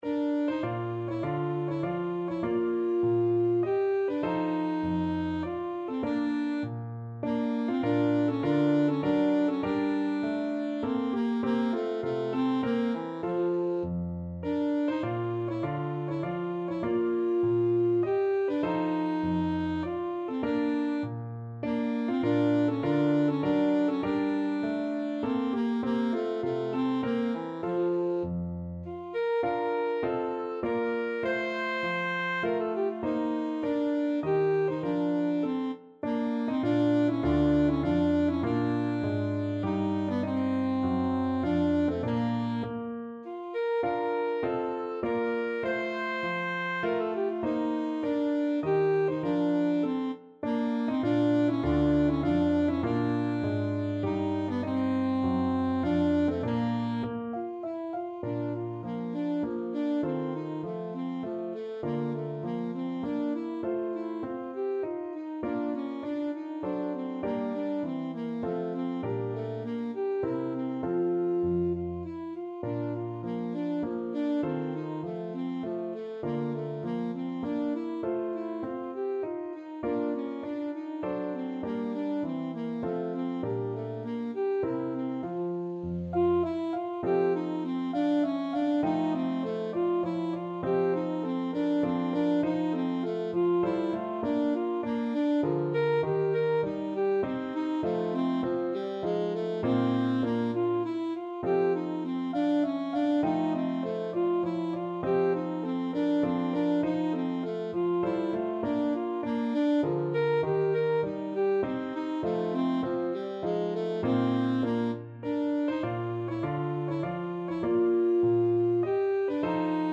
Alto Saxophone
3/4 (View more 3/4 Music)
Classical (View more Classical Saxophone Music)